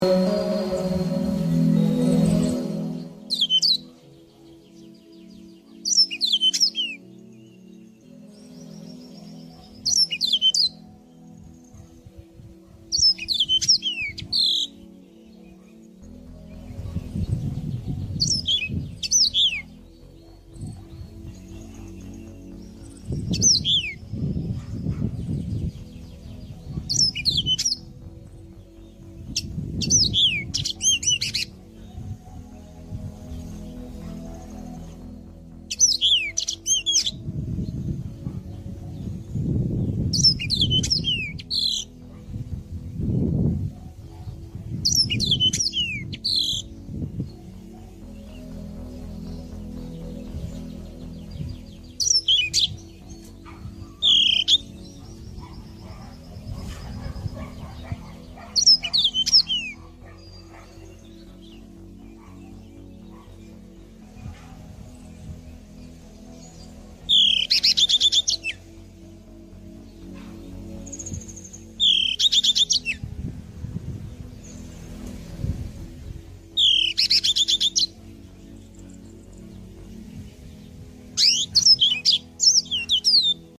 Mozambik Kanaryasının Ötüşü Nasıldır?
• Erkekler, ötüş konusunda oldukça yeteneklidir.
• Sesleri, kanaryalara göre daha tiz fakat nağmeli ve müzikaldir.
• Özellikle günün sabah ve akşam saatlerinde şarkı şeklinde öterler.
mozambik-kanaryasi.mp3